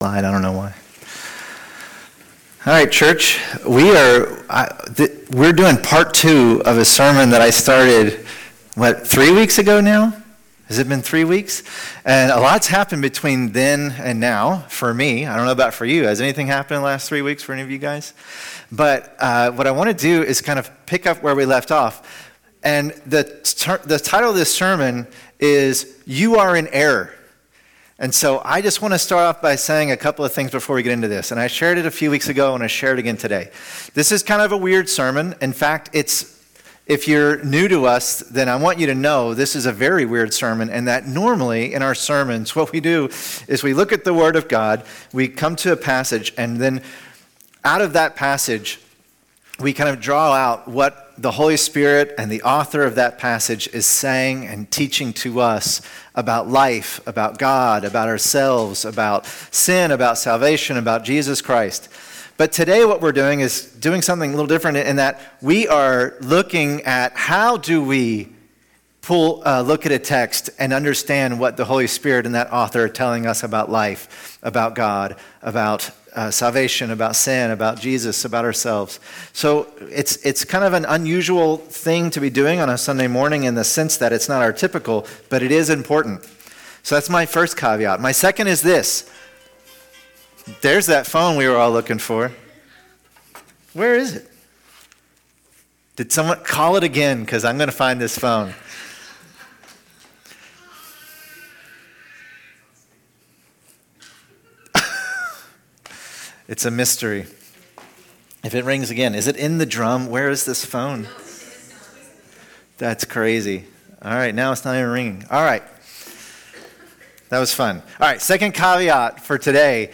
Sermons by Fellowship Church Dedham